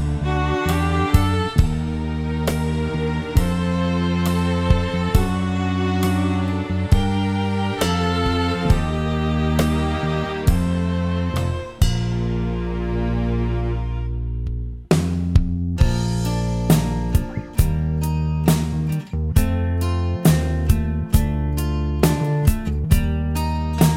no Piano Pop (1980s) 3:51 Buy £1.50